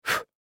На этой странице собраны звуки горящих свечей – от тихого потрескивания до ровного горения.
Свеча - Альтернативный вариант